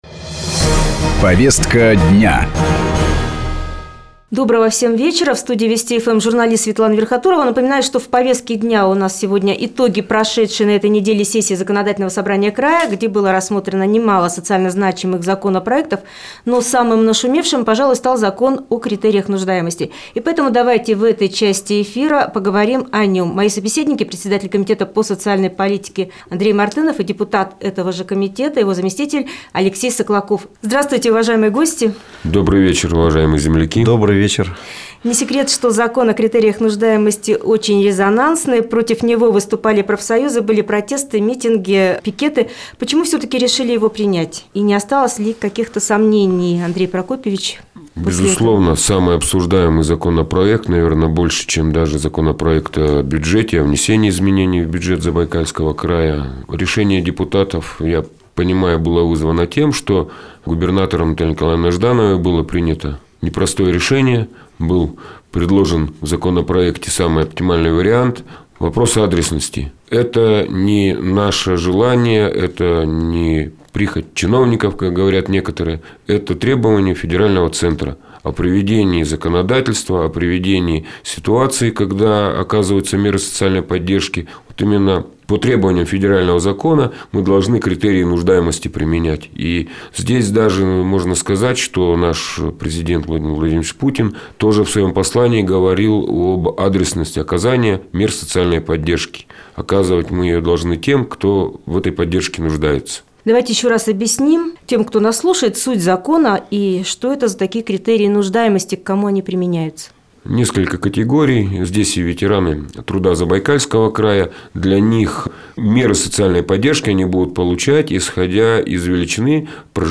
Закон о критериях нуждаемости - не прихоть чиновников. Комментарий главы комитета по социальной политике - в "Вестях-FM"